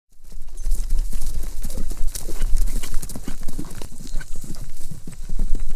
Horse Galloping